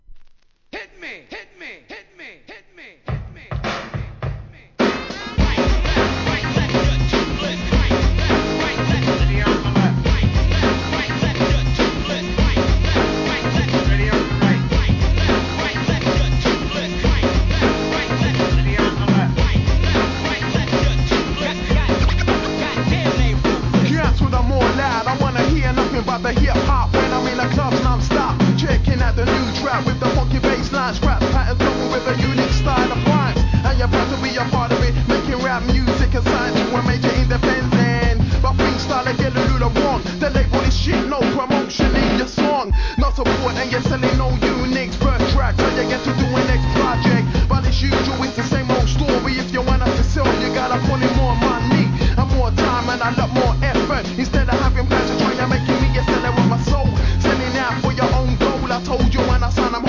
HIP HOP/R&B
UK NEW SCHOOL!!!渋いサンプリングのプロダクションに荒々しいRAP